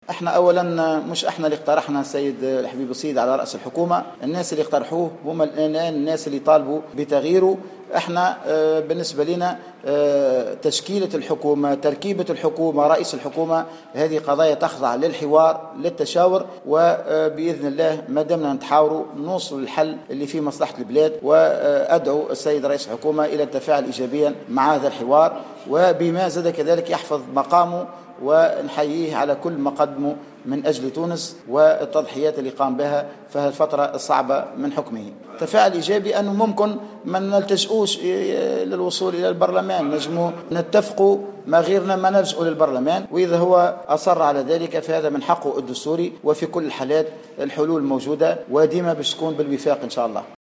وفي رده على سؤال لمراسلة "الجوهرة أف أم"، أوضح الهاروني أنه يتمنى تفادي لجوء رئيس الحكومة لمجلس نواب الشعب حول سحب الثقة من حكومته من عدمه.